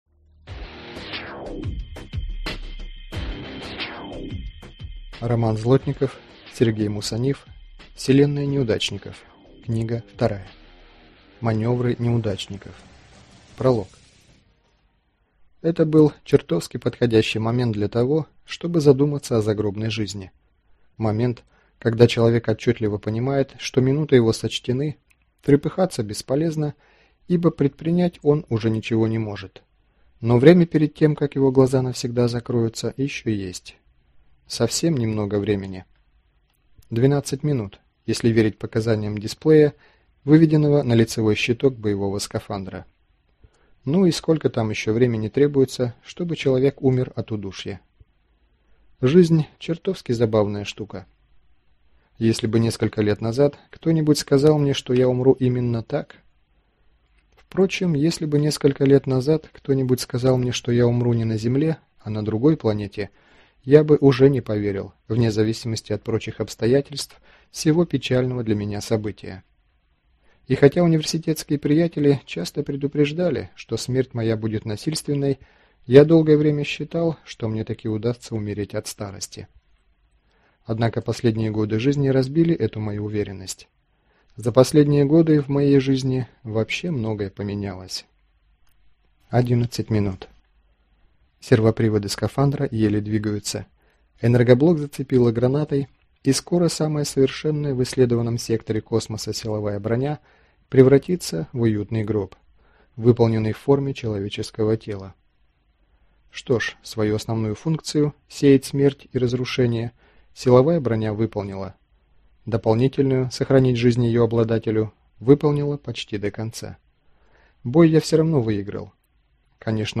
Аудиокнига Маневры неудачников | Библиотека аудиокниг